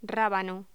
Locución: Rábano
Sonidos: Voz humana